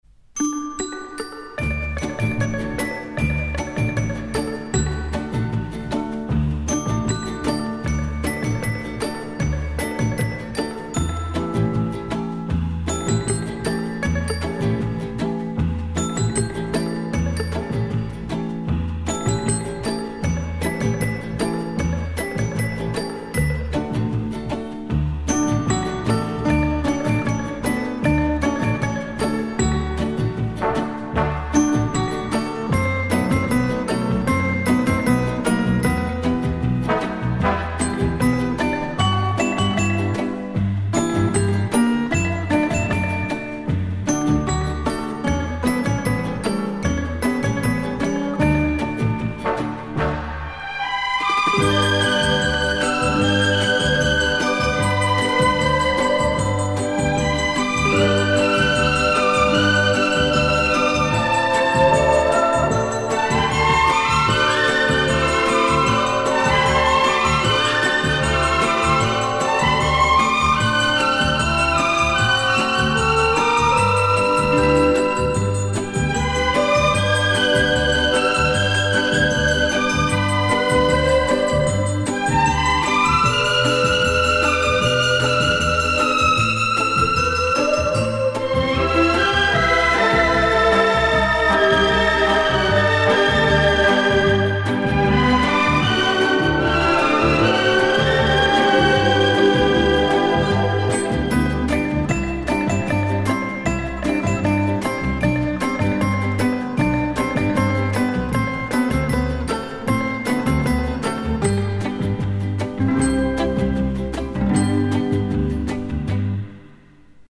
Genre:Jazz
Style:Smooth Jazz, Easy Listening